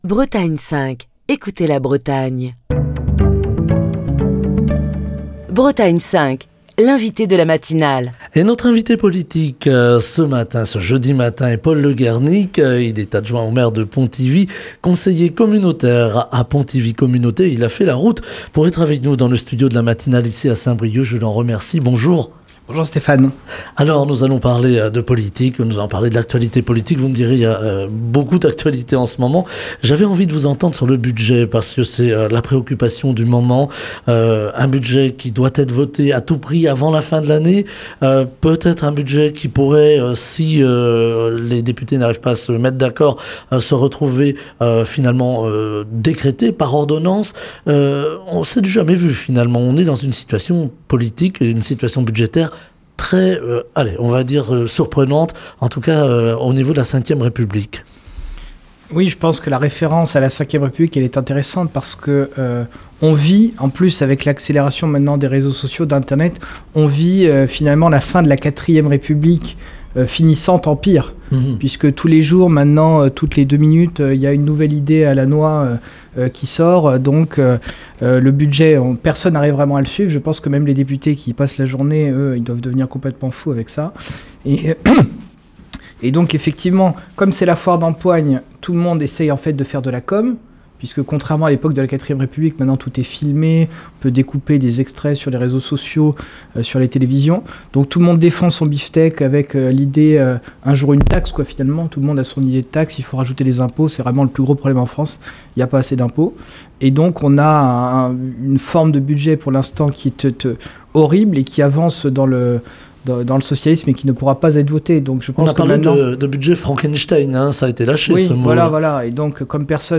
Paul Le Guernic, adjoint au maire de Pontivy et conseiller communautaire à Pontivy Communauté, était ce jeudi l’invité politique de la matinale de Bretagne 5.